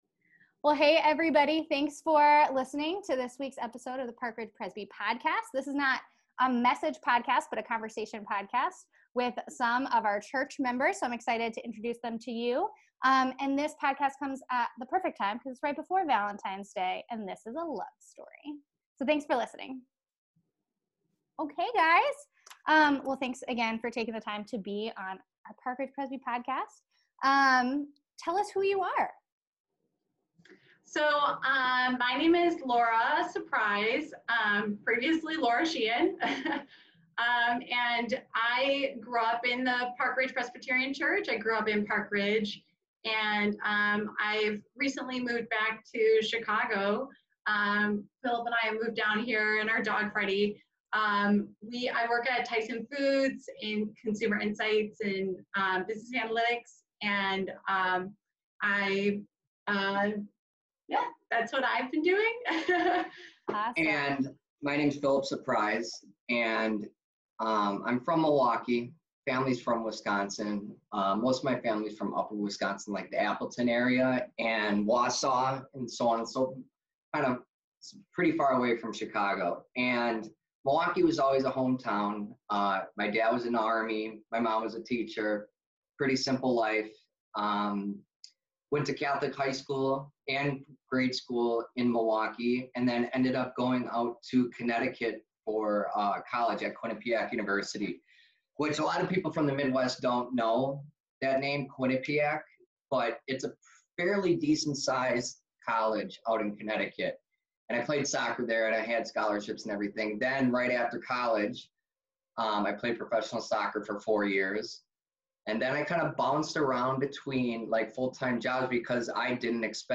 This special conversation